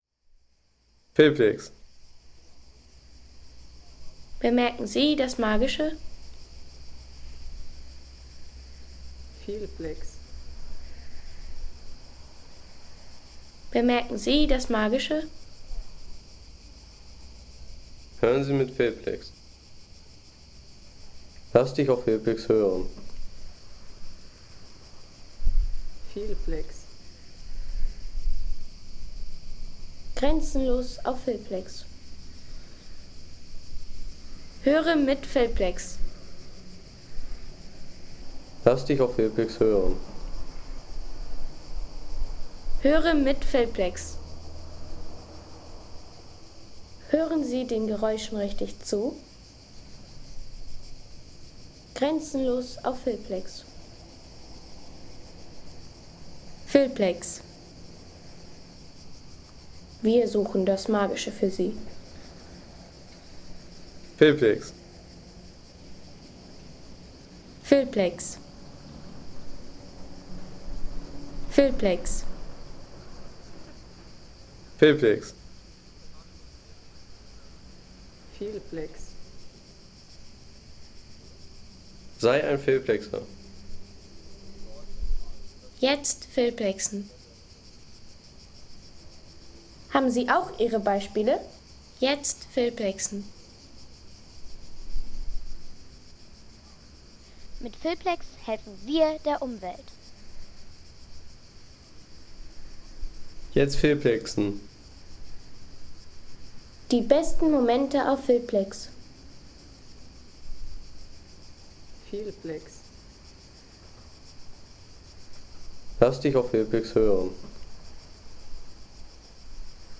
Festung Klis | Historische Klangkulisse über Split
Authentische Atmosphäre der Festung Klis in Kroatien mit historischem Umfeld und weitem Küstenblick.
Eine stimmungsvolle Festungsatmosphäre aus Kroatien mit Panoramablick, historischem Charakter und starkem Reisegefühl für Filme, Dokus und Sound-Postkarten.